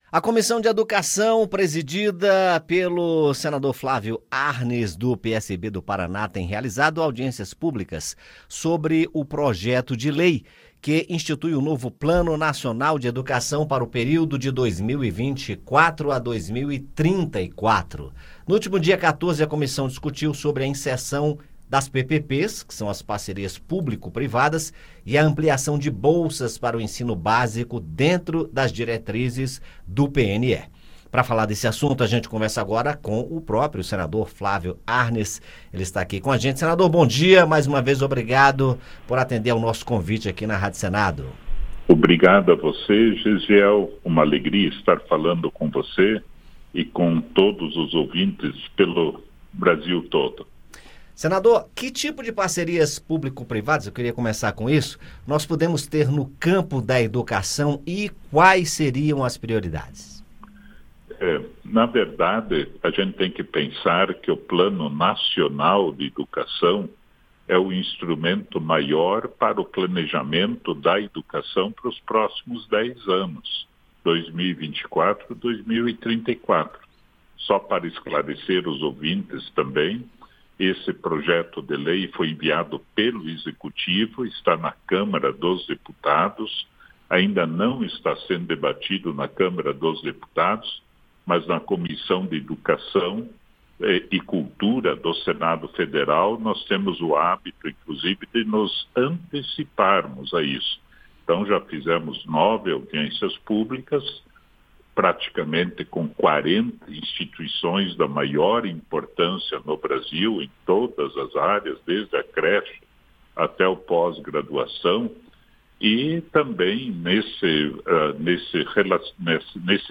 O senador Flávio Arns (PSB-PR), que preside a comissão, conversou, na Rádio Senado, sobre o papel das parcerias público-privadas e as prioridades para uma educação mais inclusiva e equitativa no Brasil. Arns também destacou a proposta de instituir um "pró-básico" — bolsas para ensino básico — e o restabelecimento da meta de investimento público na educação em 10% do produto interno bruto (PIB).